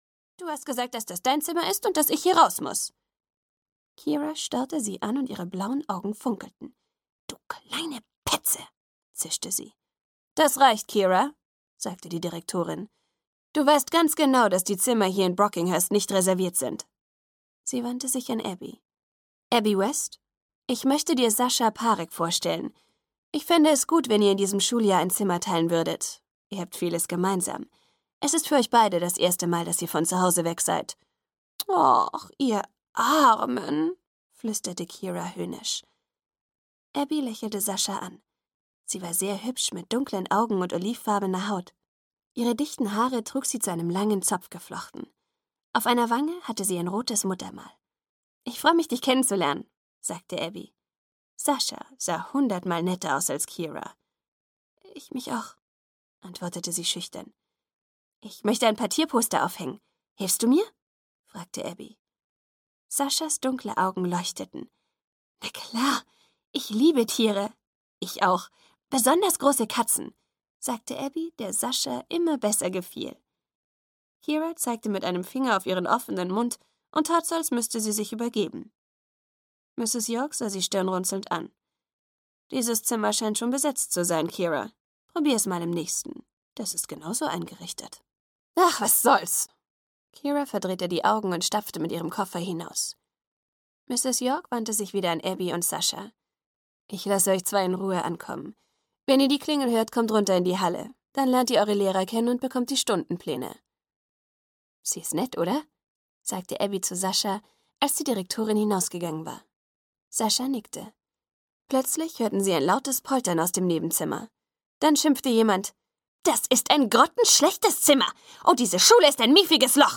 Zauberkätzchen 2: Zauberkätzchen, Band 2: Chaos im Internat - Sue Bentley - Hörbuch